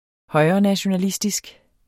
Udtale [ ˈhʌjʁʌnaɕonaˌlisdisg ]